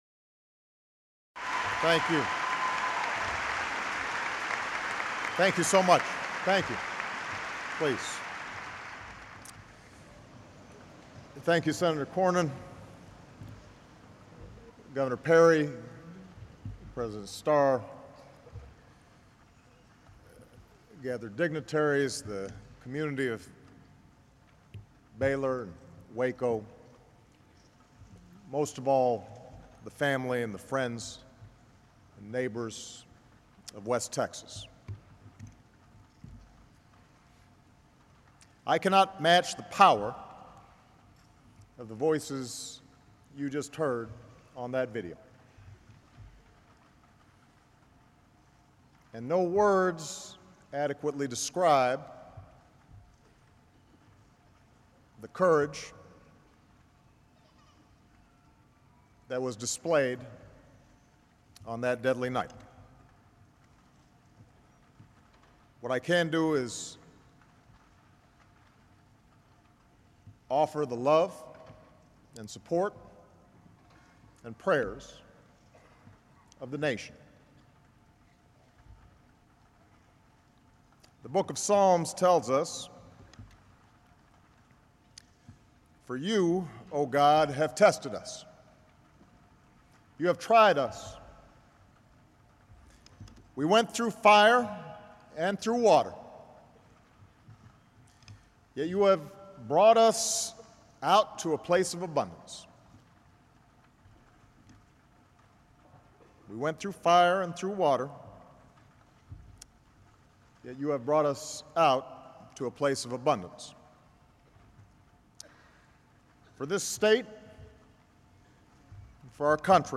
U.S. President Barack Obama speaks at a memorial service for the victims of a fertilizer plant explosion in West, Texas